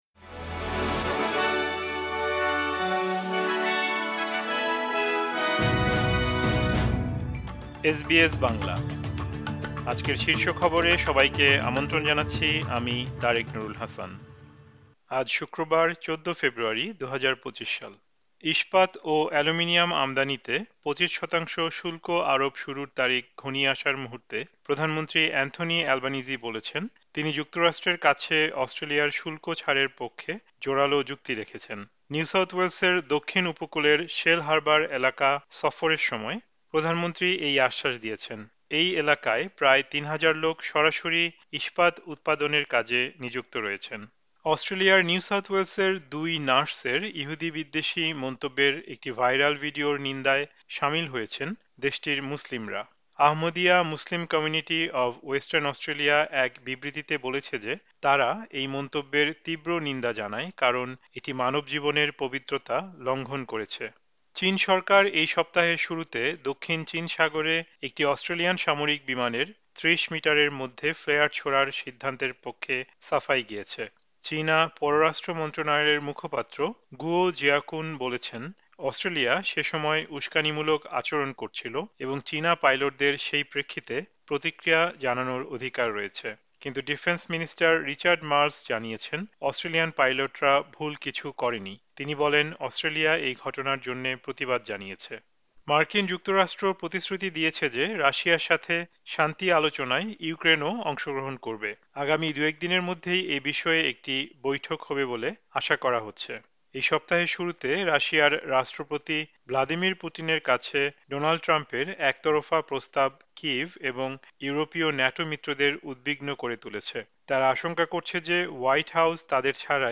এসবিএস বাংলা শীর্ষ খবর: ১৪ ফেব্রুয়ারি, ২০২৫